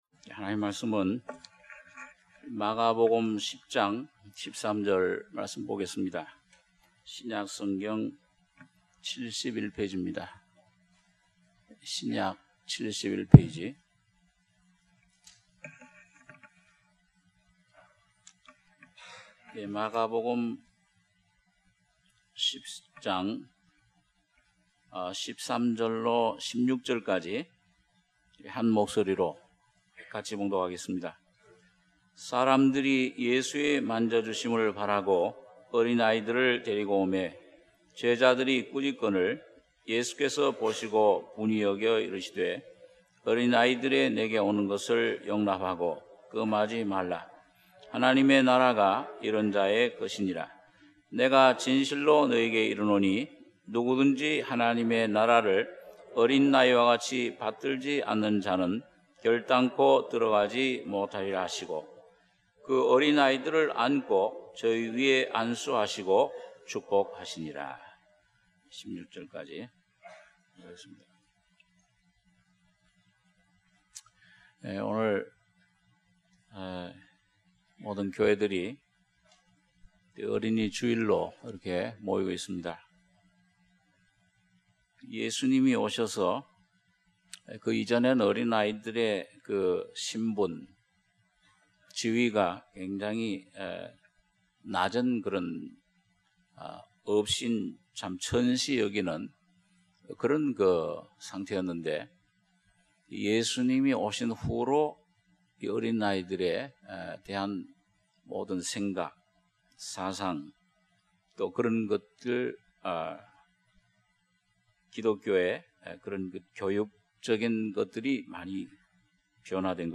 주일예배 - 마가복음 10장 13절~16절 주일1부